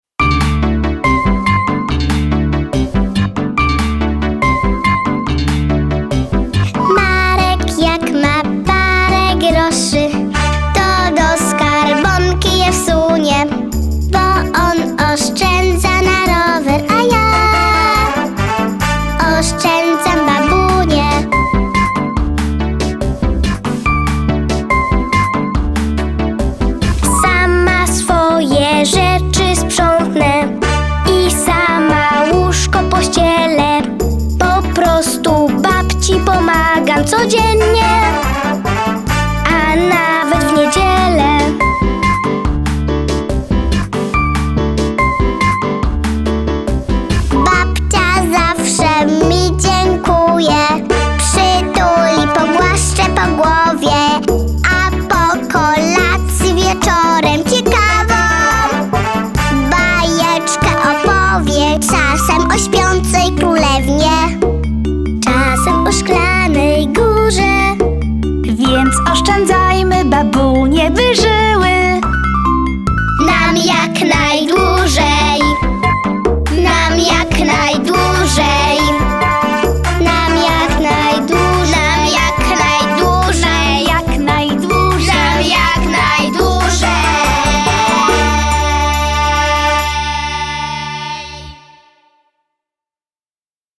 Ja też oszczędzam (wersja instrumentalno-wokalna)
Genre: Blues.